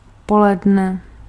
Ääntäminen
IPA: /mi.di/